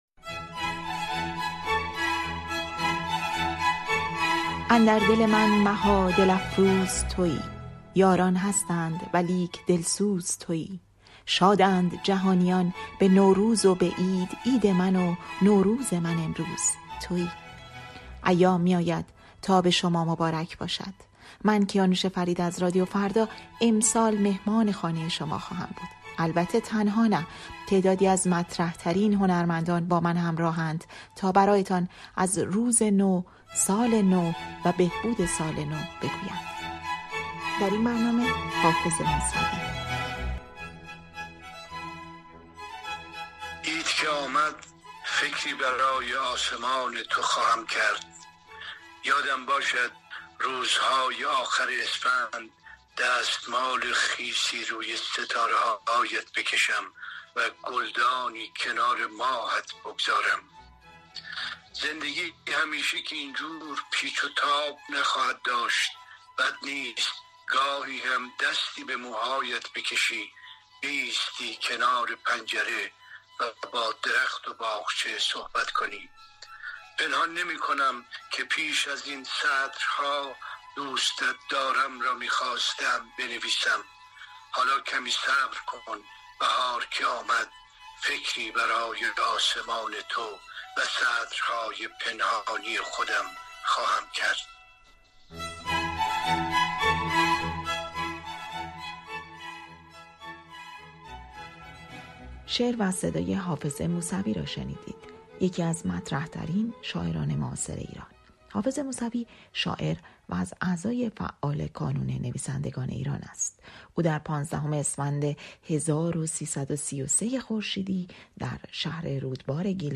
گفت‌وگوی نوروزی با حافظ موسوی